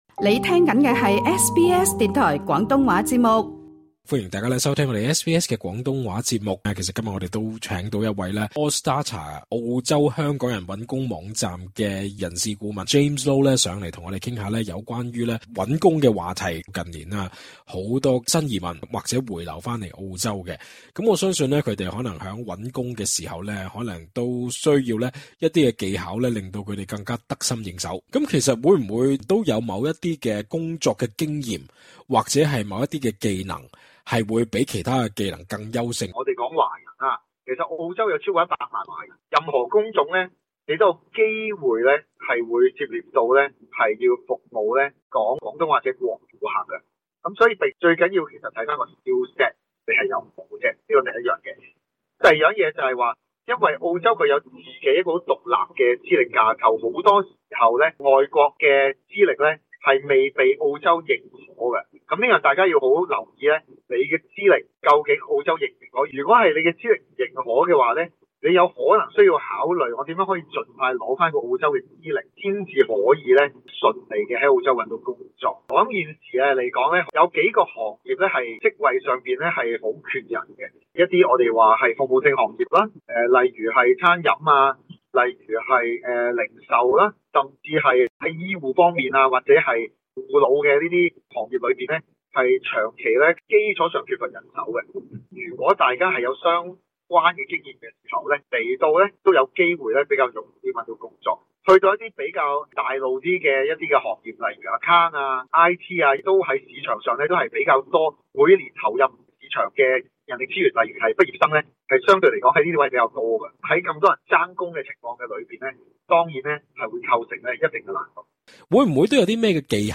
不少人聽過：「在澳洲求職一定要有本地工作經驗！」，這句話不假，但有資深人力資源顧問在接受SBS中文廣東話節目訪問時強調，澳洲僱主眼中的「本地工作經驗」、未必是大家是想象般狹窄，他亦建議新到埗的求職者、可先從了解澳洲的職場文化及僱主心態入手以減少「走寃枉路」的機會。